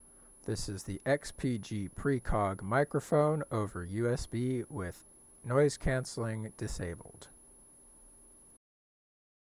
Using the USB sound card with XPG’s ENC noise-canceling disabled, the audio quality was similar, but a loud, high pitched whine was present.
Microphone-Test-Samples-XPG-Precog-USB-noENC.mp3